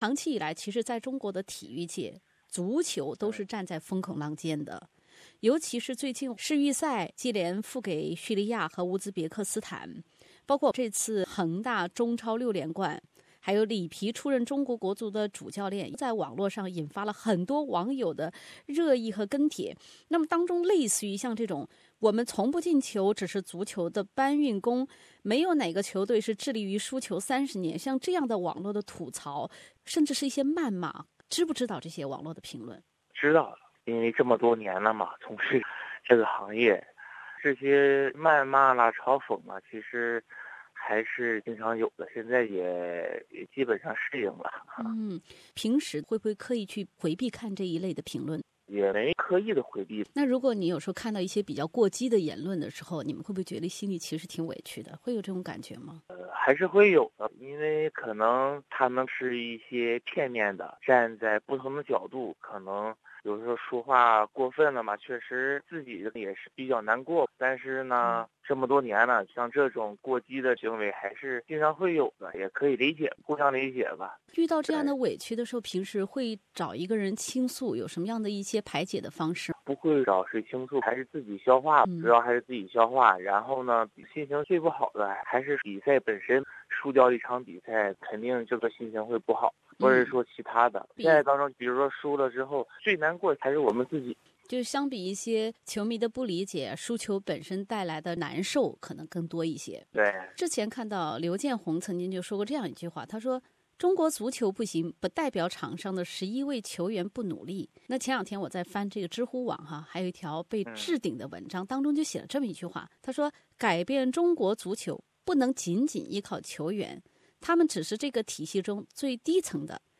专访中国国家男子足球队队长冯潇霆。